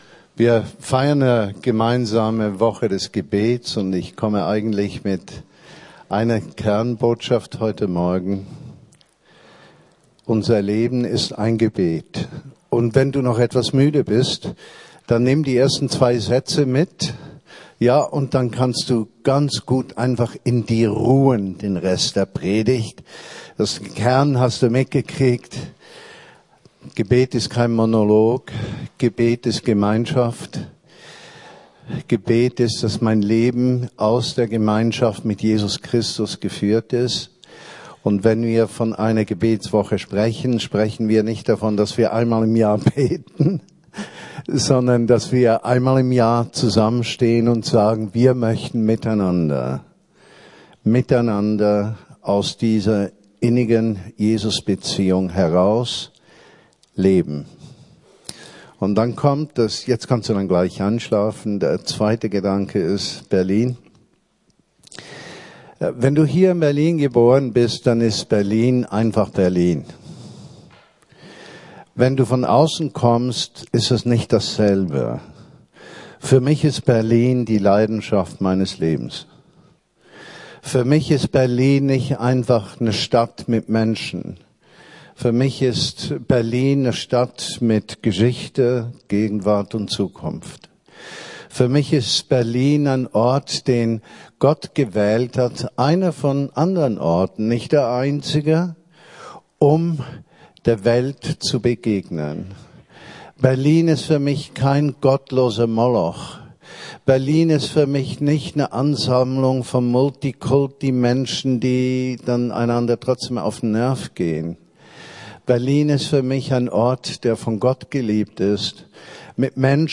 Gebet ~ Predigten der LUKAS GEMEINDE Podcast